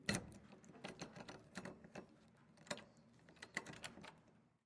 关闭前门
描述：记录门的关闭和落入其锁中
Tag: 前门 关闭